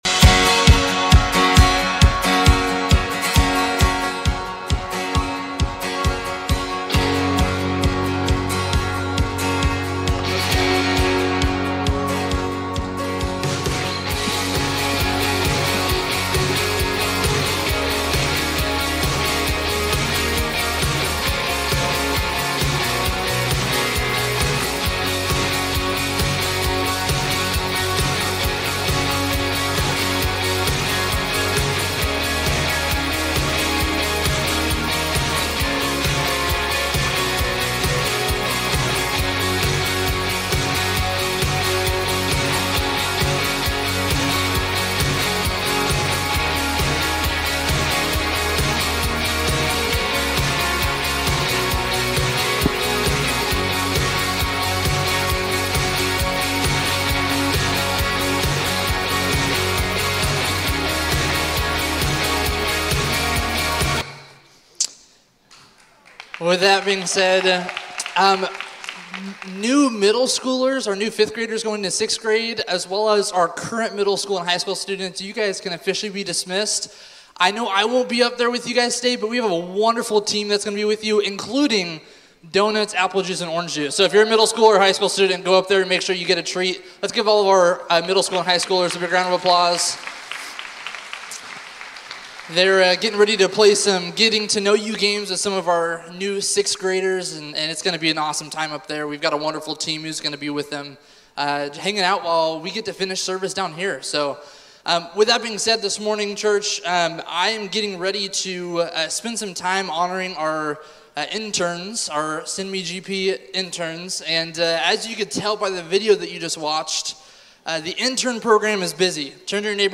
A message from the series "2024 Sunday Specials."